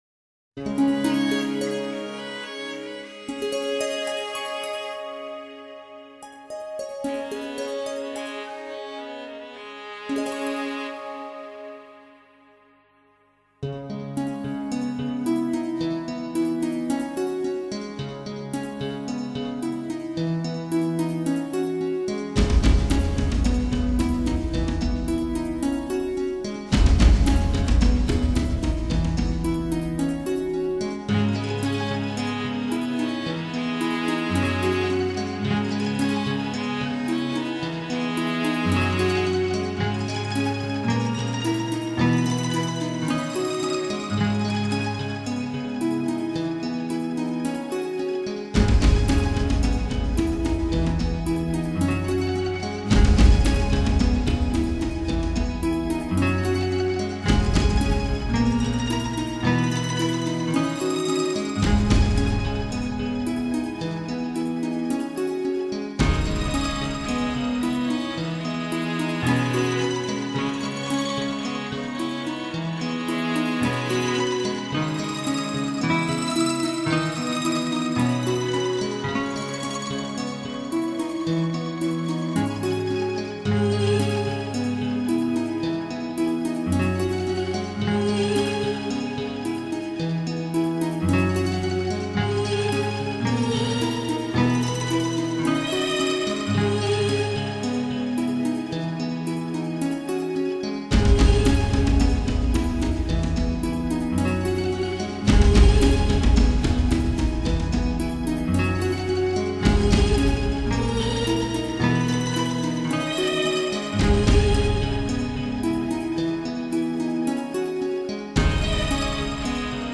精挑细选的养心音乐，
能让您安神定意，舒展身心，